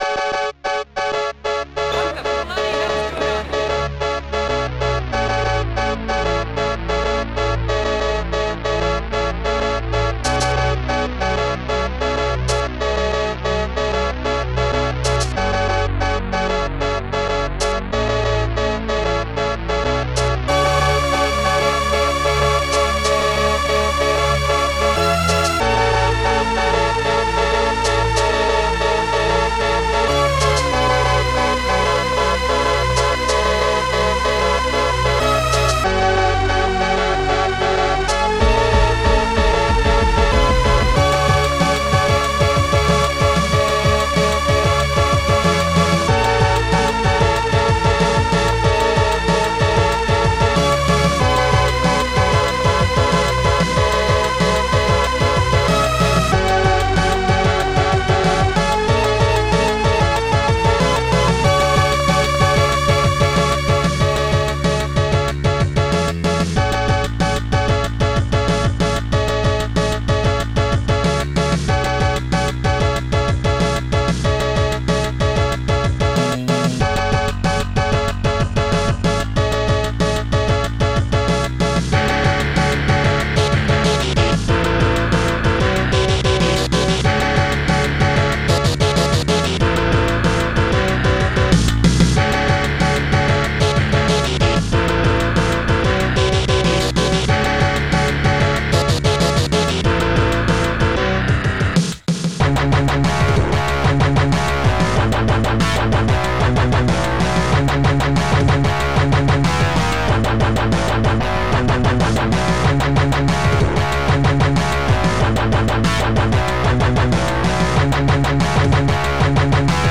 OctaMED Module
Type MED/OctaMED (4ch)